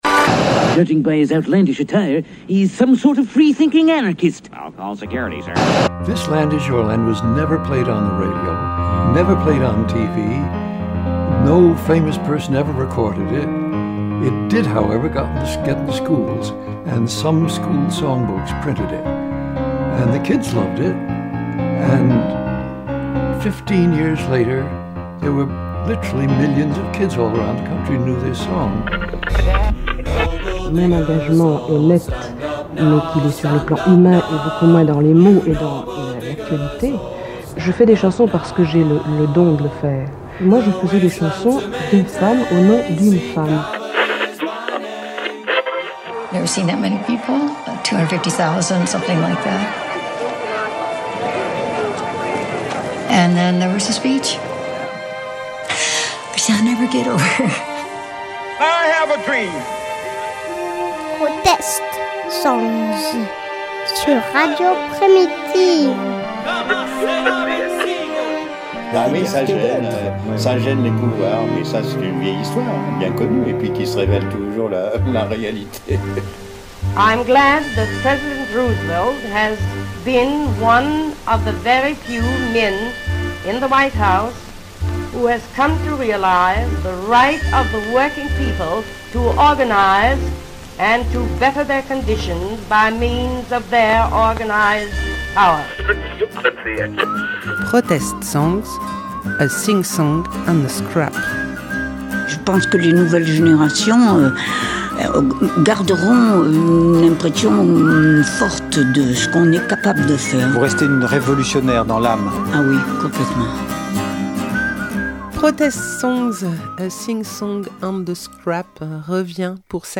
🎧 Émission 4 - Protest songs